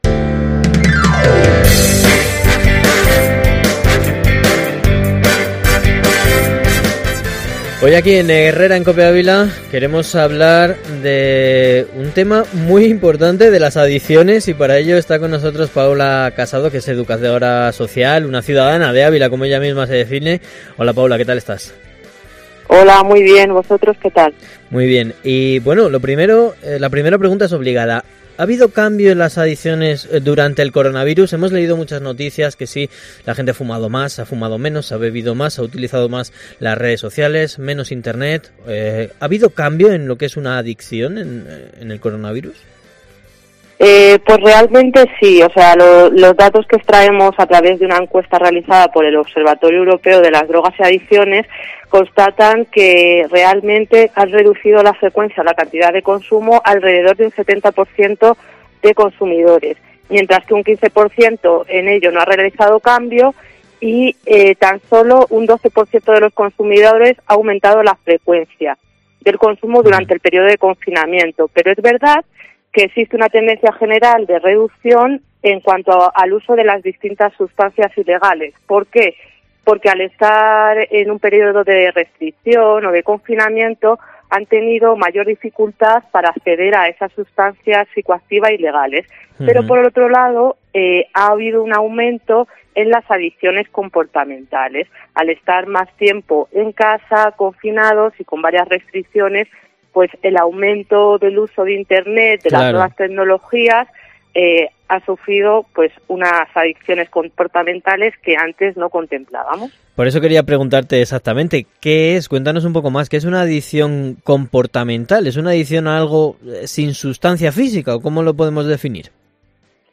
explica en COPE las nuevas adicciones comportamentales que se acentúan con el coronavirus (Escuchar entrevista).